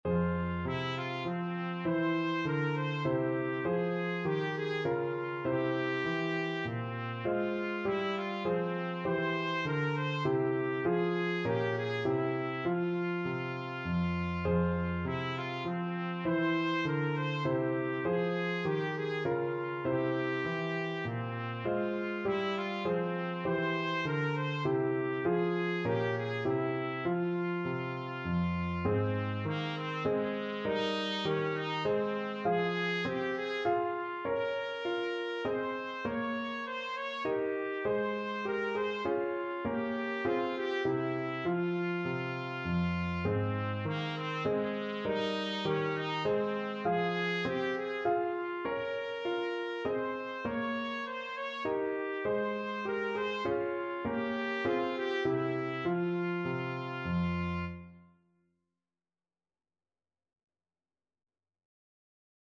Trumpet
F minor (Sounding Pitch) G minor (Trumpet in Bb) (View more F minor Music for Trumpet )
Moderato
3/4 (View more 3/4 Music)
Classical (View more Classical Trumpet Music)
purcell_air_TPT.mp3